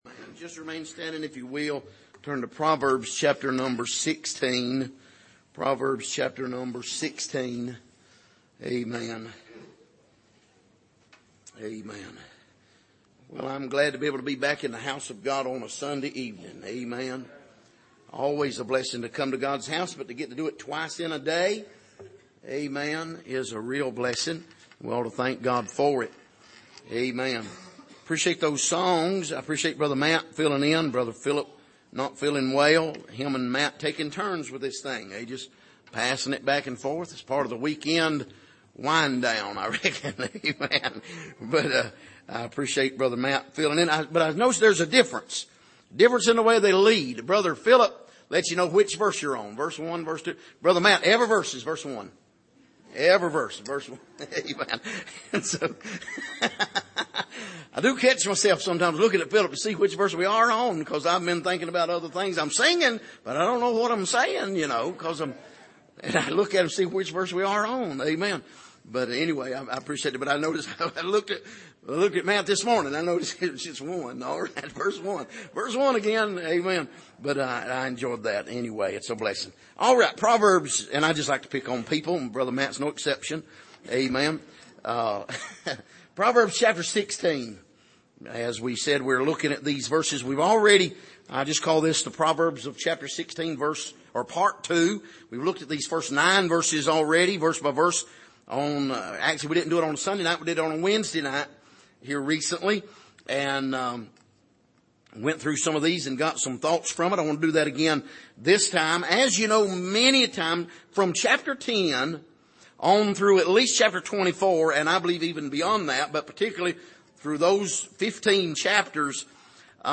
Passage: Proverbs 16:10-17 Service: Sunday Evening